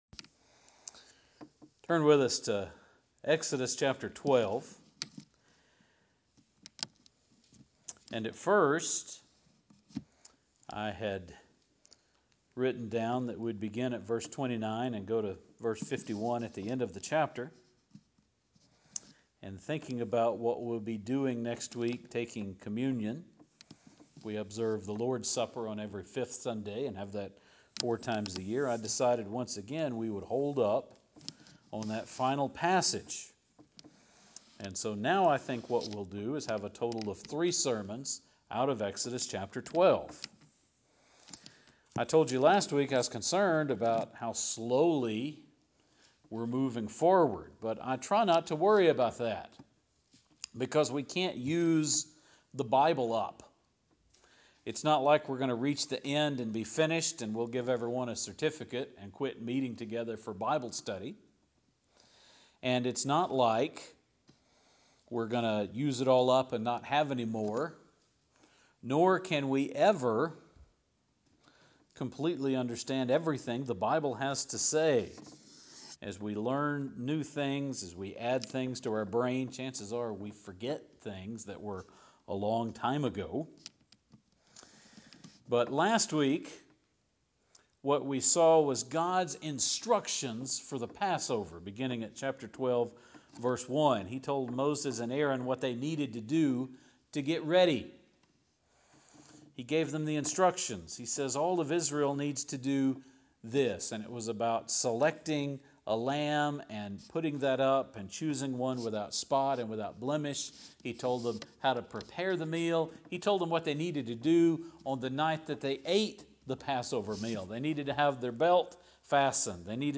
Last week, in the first passage of Exodus 12, the instructions for preparing the Passover sacrifice and eating the Passover meal were given. In this sermon we will not only get to see Israel allowed to leave but commanded to get out quickly.